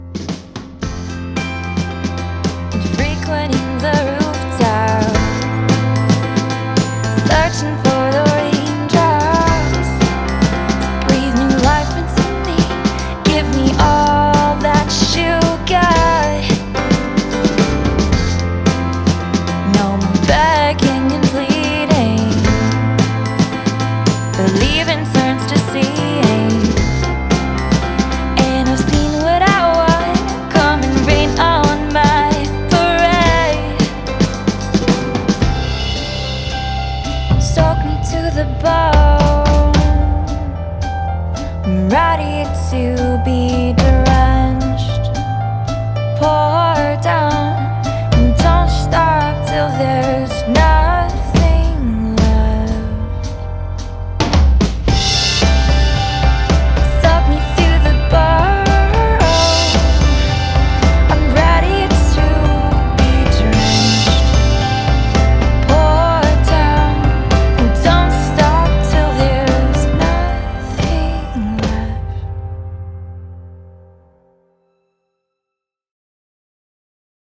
Styled Soul/Pop with an Acoustic heart